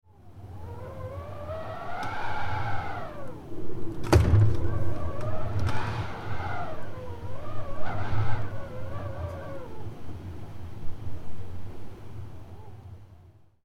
Howling-wind-with-a-loud-slamming-door-sound-effect.mp3